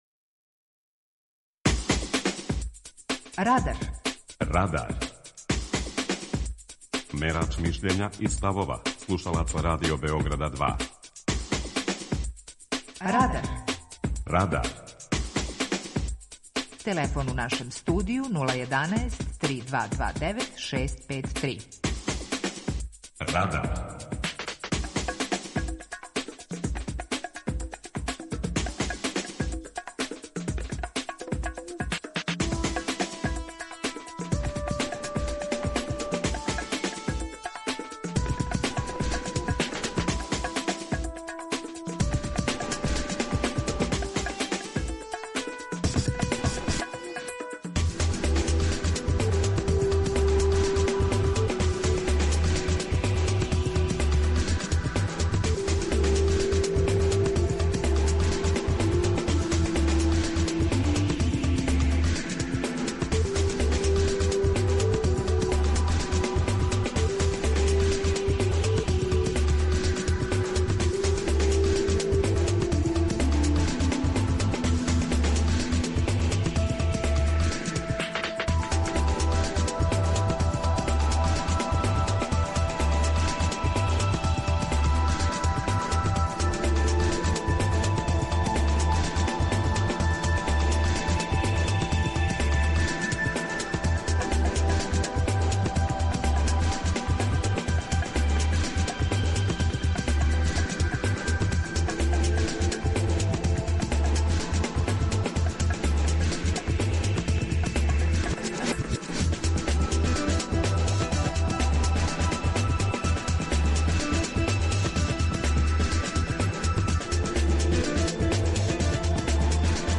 У сусрет новим изборима у Српској академији наука и уметности Радар пита: Kо треба да буде академик? преузми : 19.12 MB Радар Autor: Група аутора У емисији „Радар", гости и слушаоци разговарају о актуелним темама из друштвеног и културног живота.